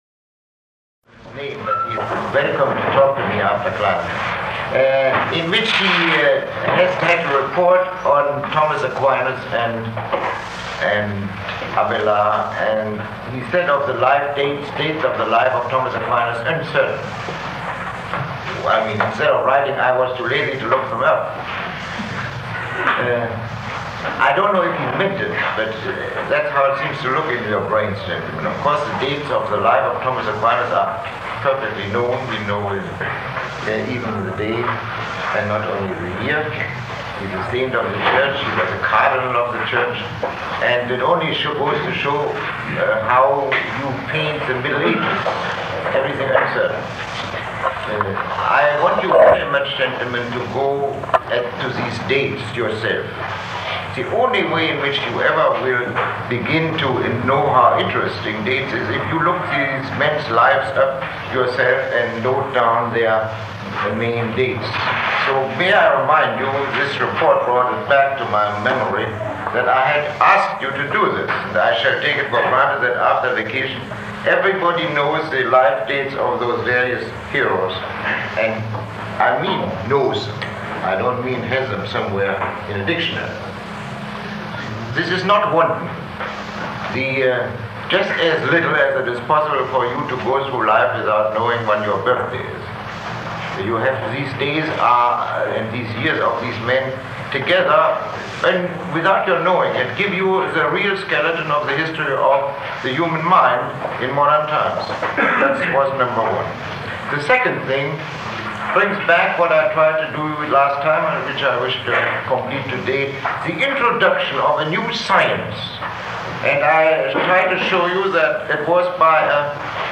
Lecture 14